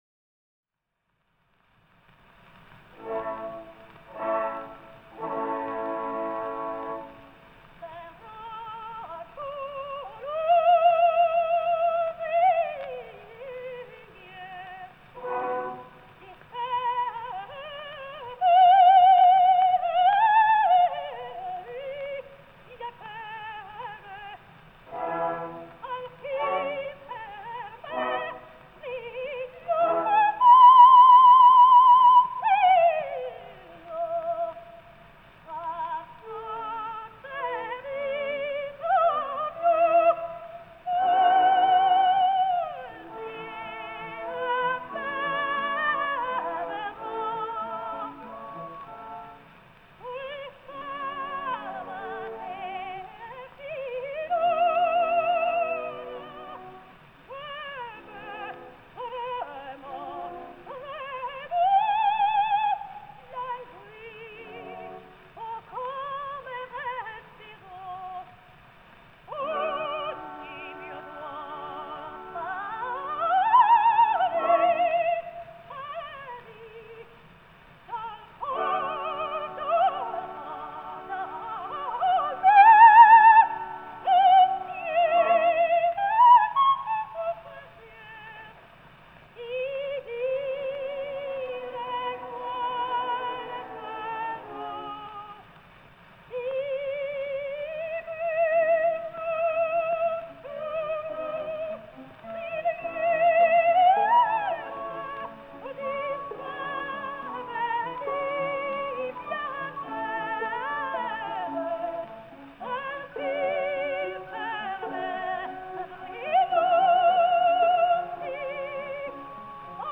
ТЕТРАЦЦИНИ (Tetrazzini) Луиза (29, по др. данным, 28 VI 1871, Флоренция - 28 IV 1940, Милан) - итал. певица (колоратурное сопрано).